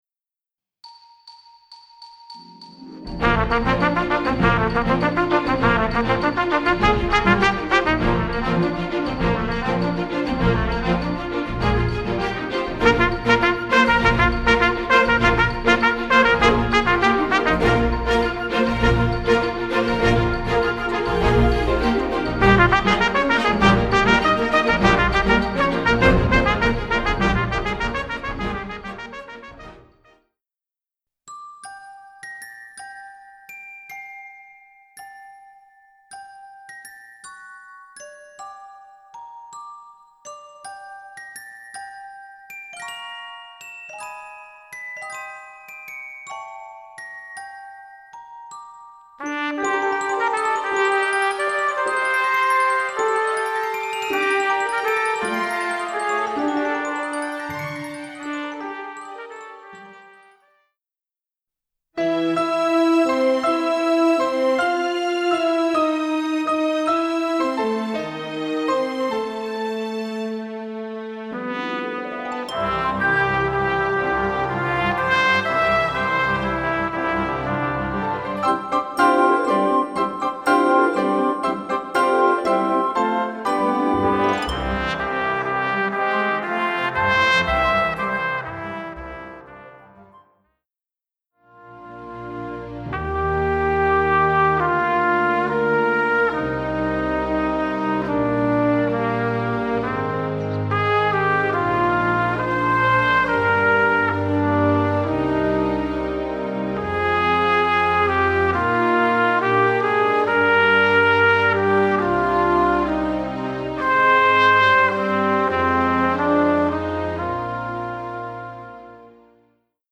Voicing: Trumpet w/ Audio